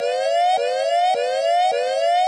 Alarm1.ogg